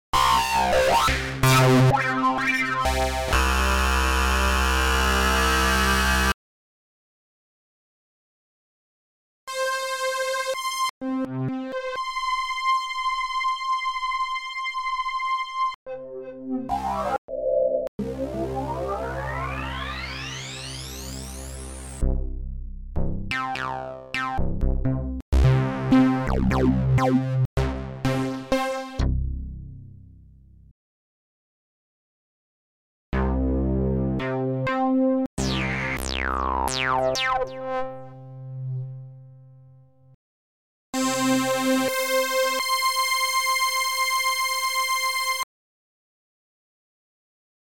This pack contains samples of original timbres of the synthesizer Access VIRUS.
arpeggios,
atmosphere,
basses,
pads,